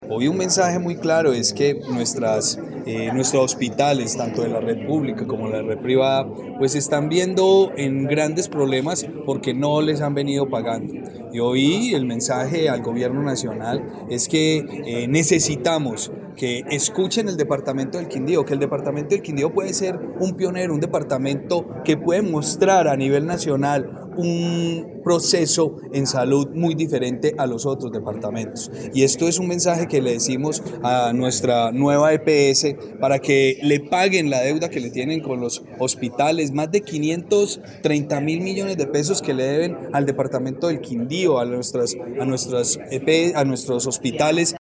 Durante rueda de prensa, el Gobernador del Quindío, Juan Miguel Galvis Bedoya, anunció la contención de algunos servicios en el hospital universitario San Juan de Dios para los usuarios de la Nueva EPS, como consecuencia del no pago de la cartera al centro asistencial, que asciende a una suma de $26.215 millones 113.402.
Voz Juan Miguel Galvis Bedoya, Gobernador del Quindío, rueda de prensa Nueva EPS:
Audio-Juan-Miguel-Galvis-Bedoya-Gobernador-del-Quindio-rueda-de-prensa-NUEVA-EPS.mp3